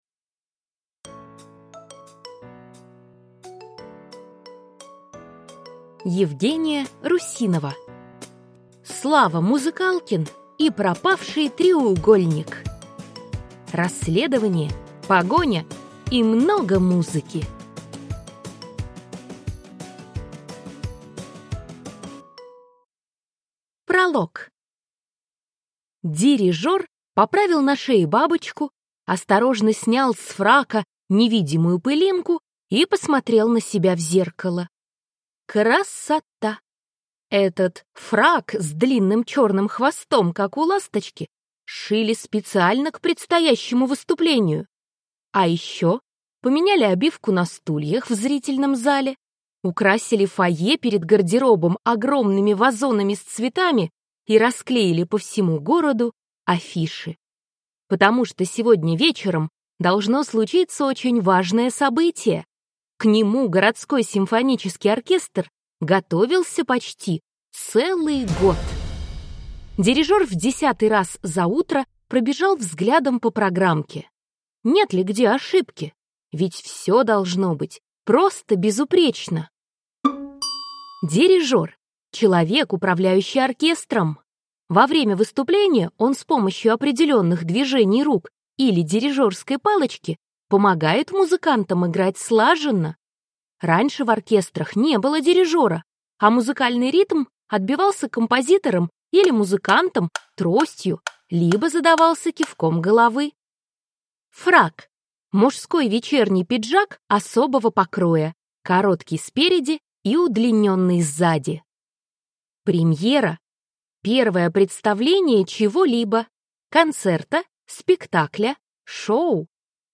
Аудиокнига Слава Музыкалкин и пропавший Треугольник. Расследование, погоня и много музыки | Библиотека аудиокниг